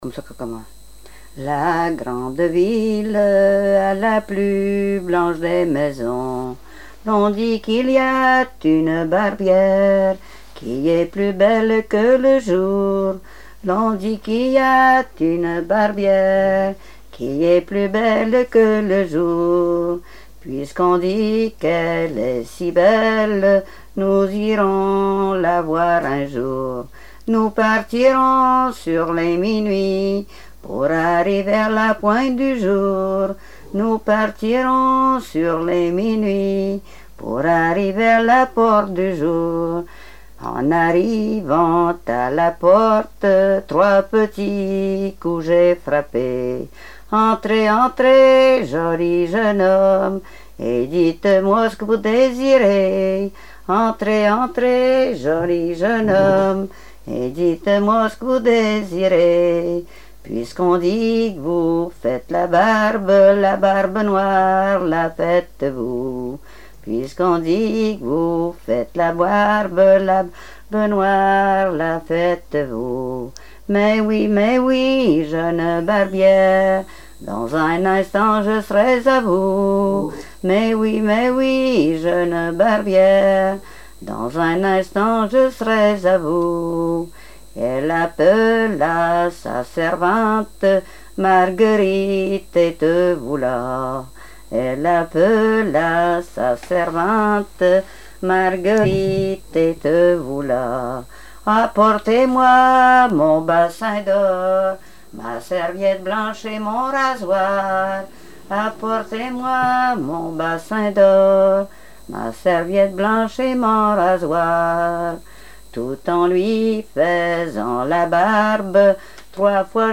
Genre laisse
Répertoire de chansons traditionnelles et populaires
Pièce musicale inédite